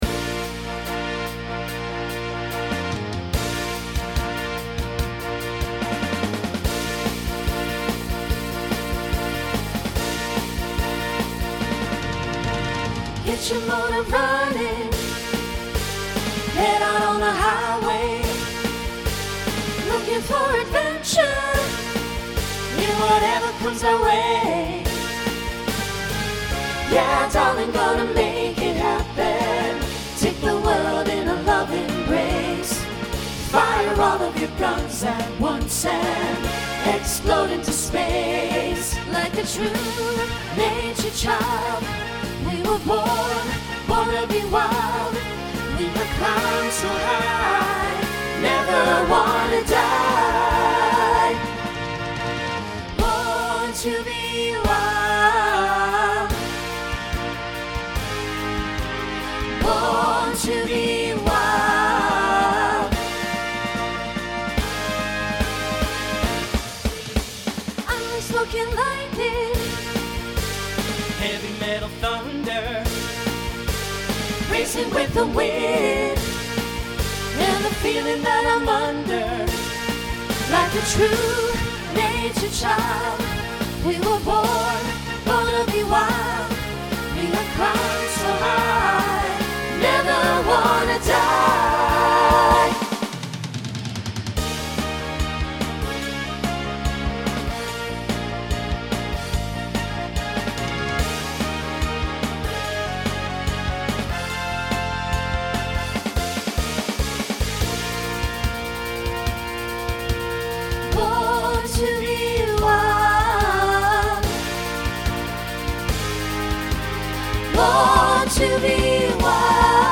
New SSA voicing for 2026.